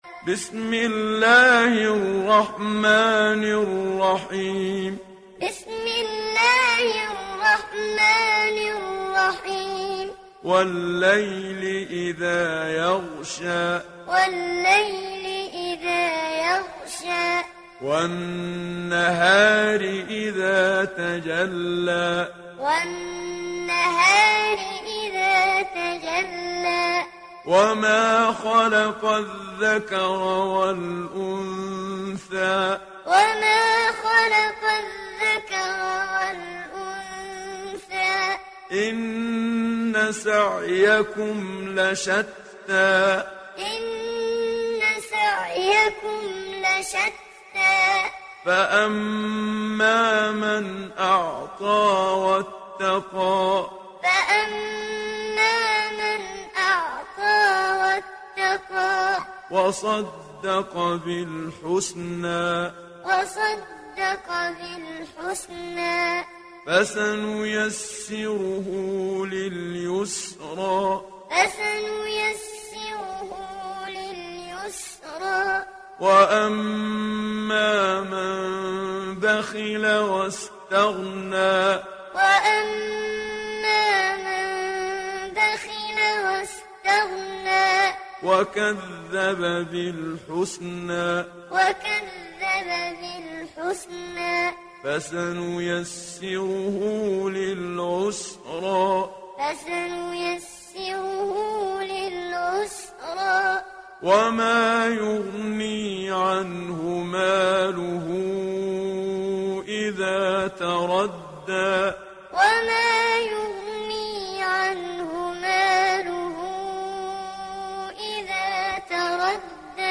Download Surat Al Layl Muhammad Siddiq Minshawi Muallim